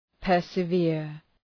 {,pɜ:rsə’vıər}